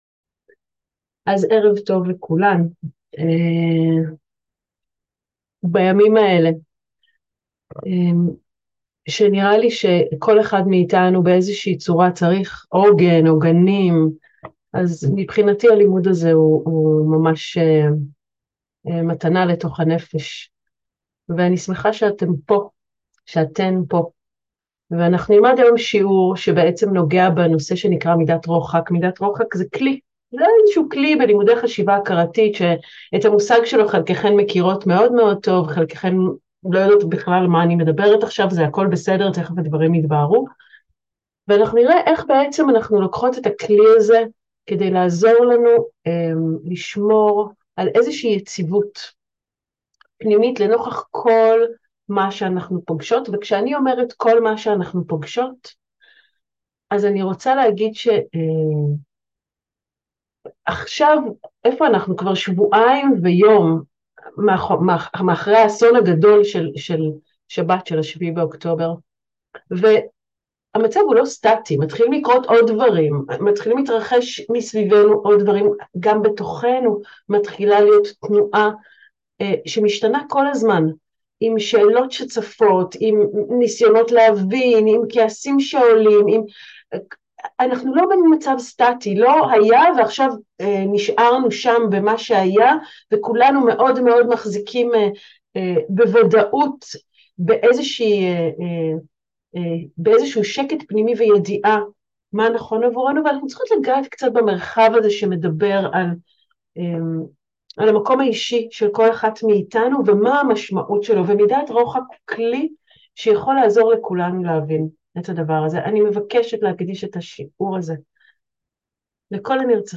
מהי מידת רוחק, ואיך היא יכולה לסייע לנו בימים אלו, מהי מטרתה? מתוך שיעורים פתוחים